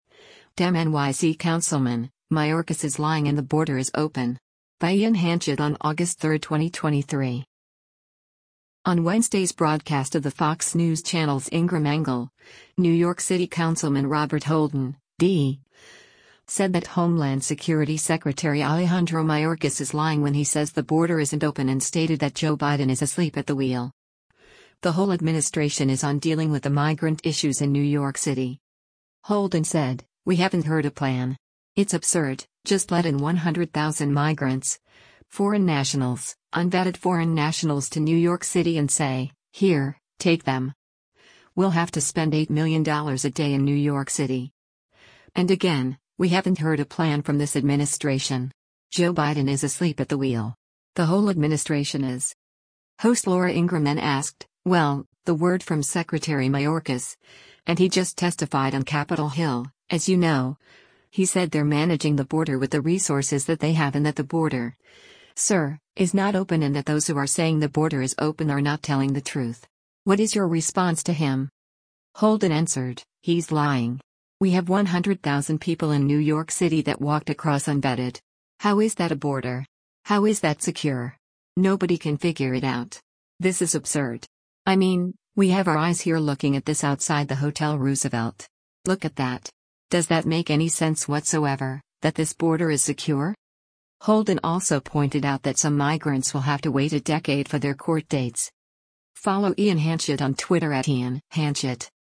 On Wednesday’s broadcast of the Fox News Channel’s “Ingraham Angle,” New York City Councilman Robert Holden (D) said that Homeland Security Secretary Alejandro Mayorkas is “lying” when he says the border isn’t open and stated that “Joe Biden is asleep at the wheel. The whole administration is” on dealing with the migrant issues in New York City.
Host Laura Ingraham then asked, “Well, the word from Secretary Mayorkas — and he just testified on Capitol Hill, as you know — he said they’re managing the border with the resources that they have and that the border, sir, is not open and that those who are saying the border is open are not telling the truth. What is your response to him?”